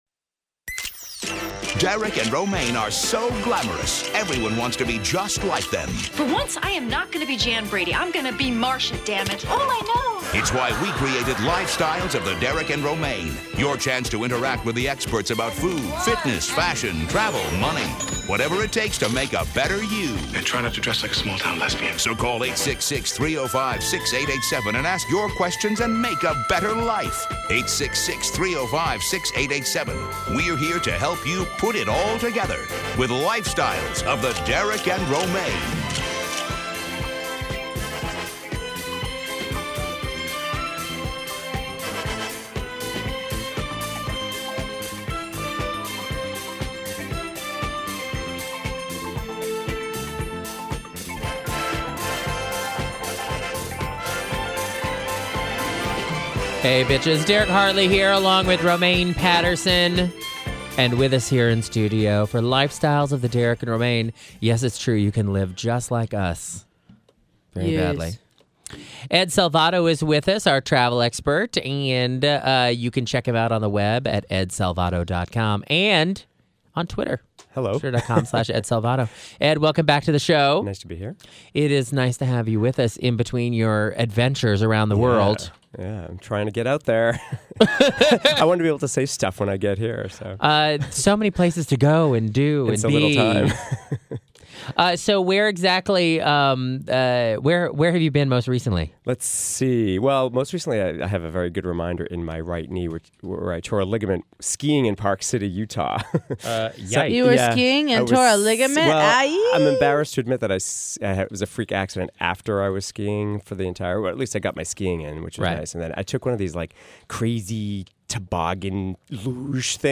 Callers threw all sorts of questions on a wide range of topics including:
His interview lasted almost 45 minutes and is available below by clicking below.